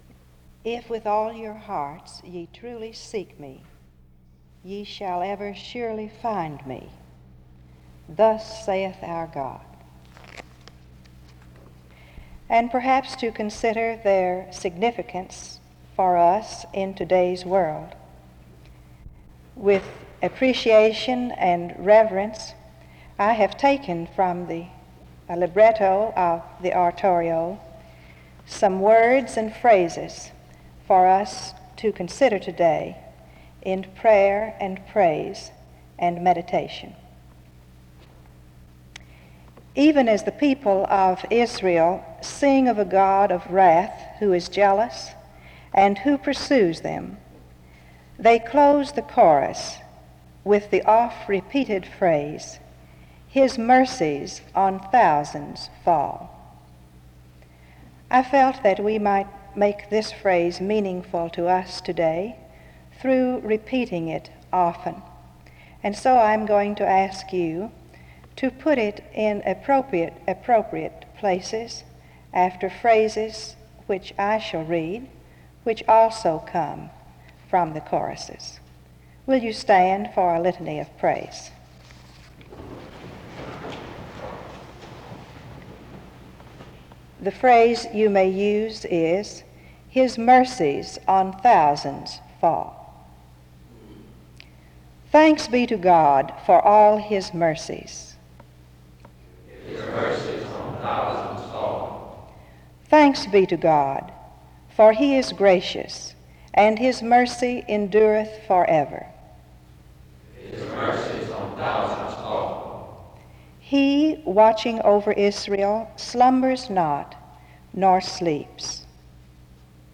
SEBTS Chapel
The service then concludes with a song.
SEBTS Chapel and Special Event Recordings SEBTS Chapel and Special Event Recordings